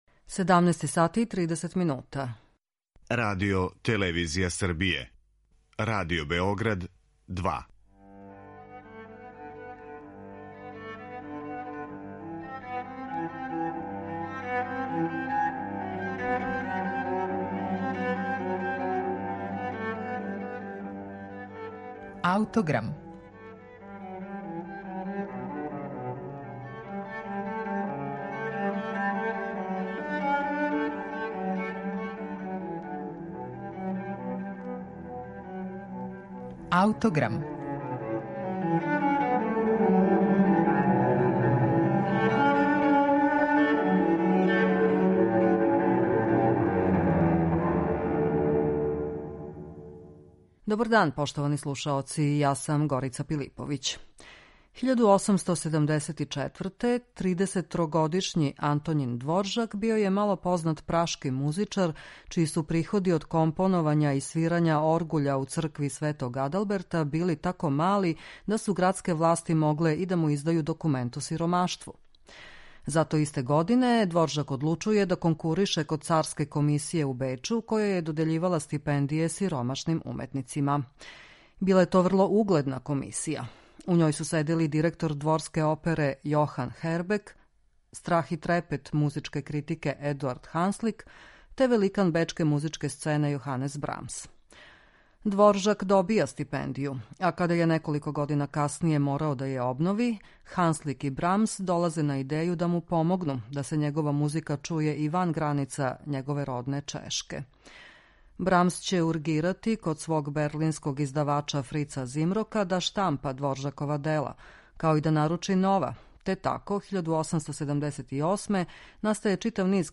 Гудачки секстет Антоњина Дворжака